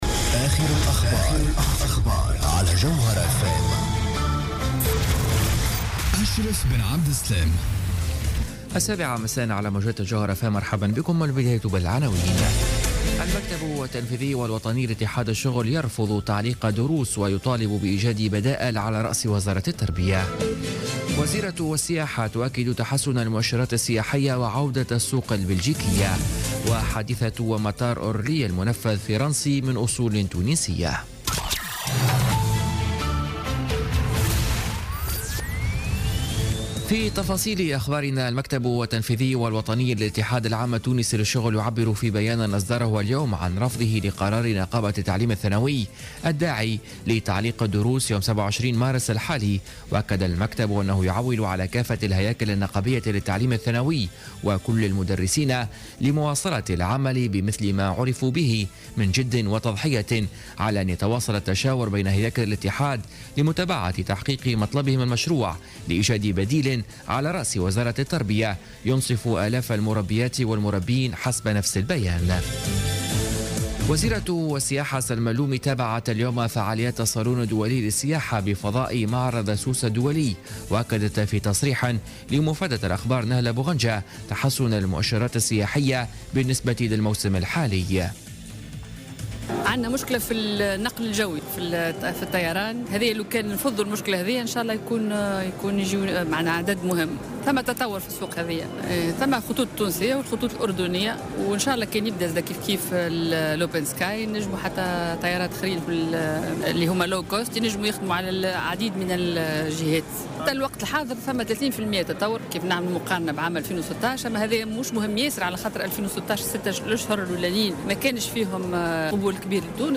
نشرة أخبار السابعة مساء ليوم السبت 18 مارس 2017